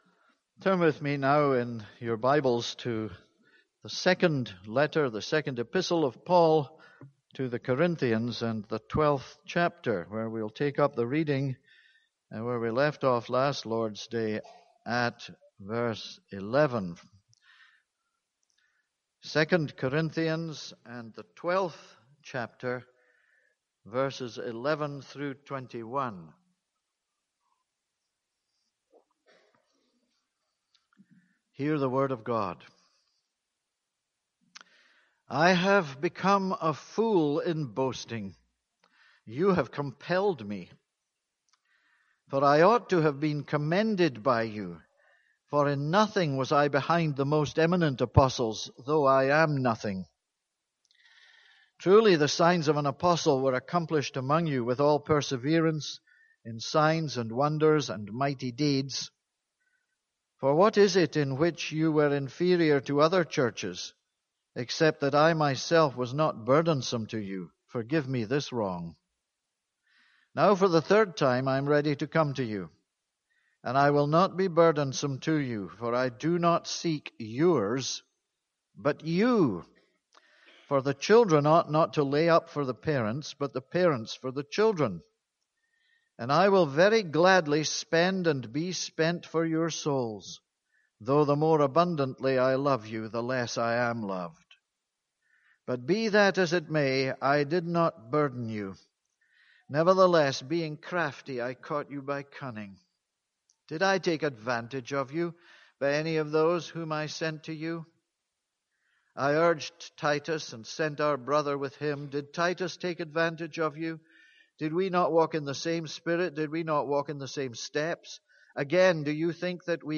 This is a sermon on 2 Corinthians 12:11-21.